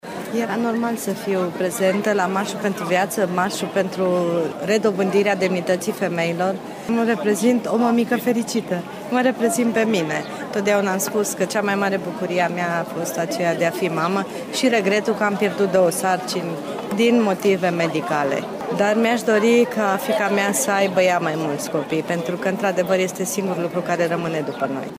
Una dintre mamele prezente a transmis mesajul său cu acest prilej: